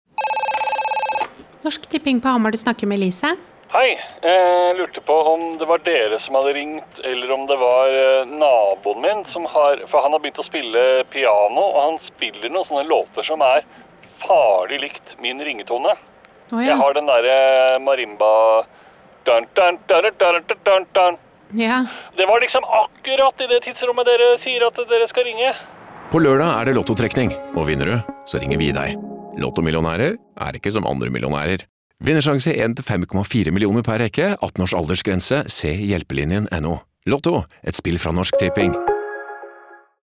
Regi må trekkes frem her og spesielt karakteren Elise er en favoritt: den stadige balanseringen mellom høflig og irritert er nydelig utført.